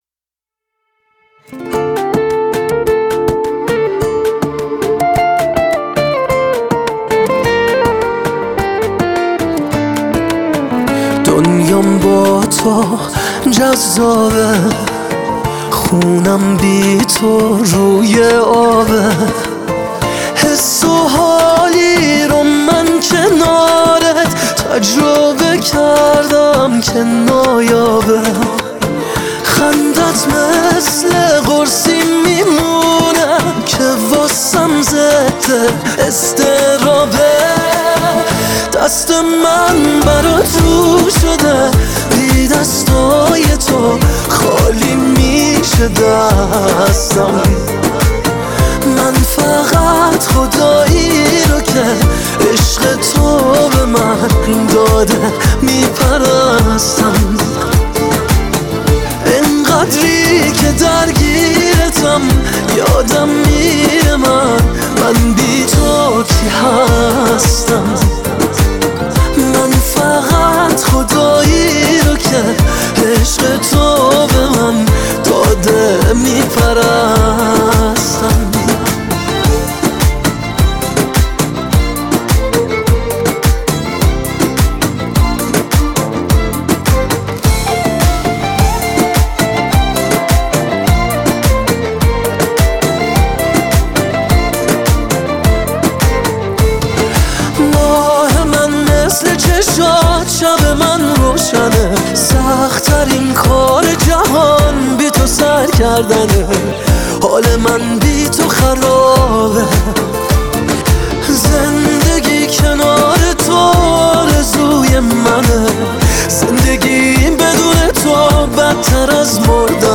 گیتار
ارکستر زهی
پاپ عاشقانه